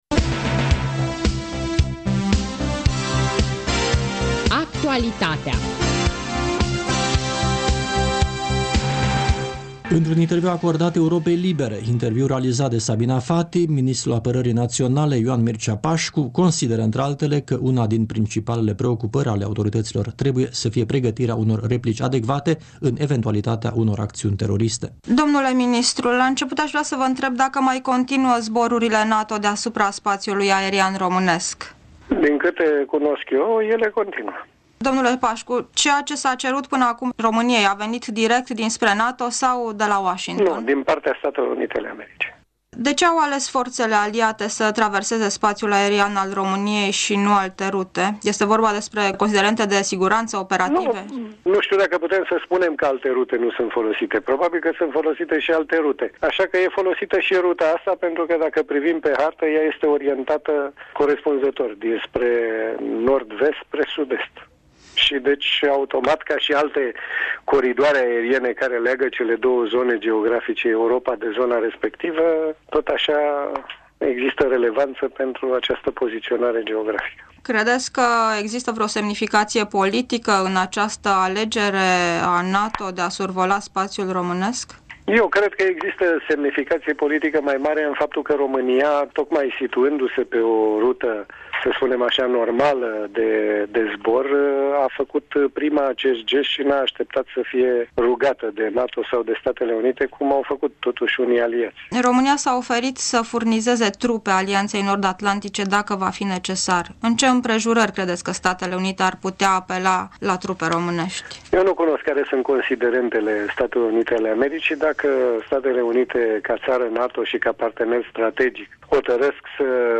Un interviu cu ministrul apărării Ioan Mircea Pașcu